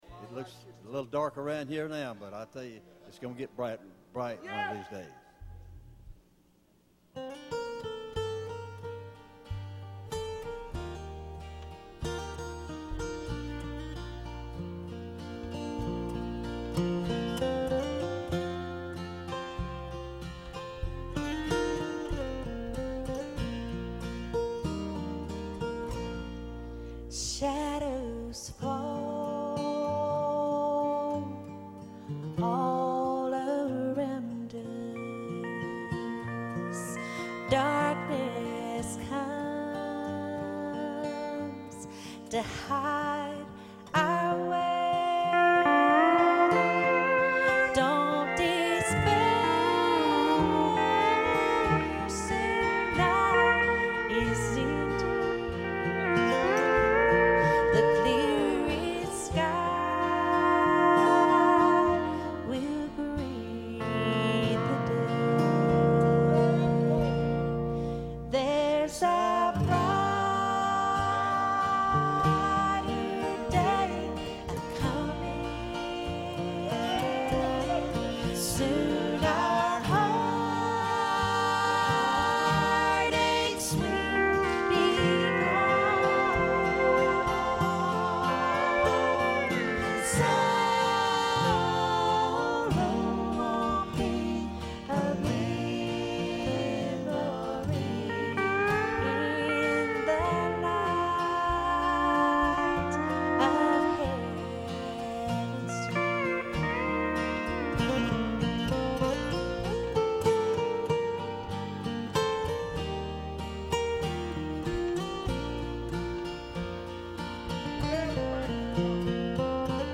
I recently remixed all the songs in stereo.
quieter steel guitar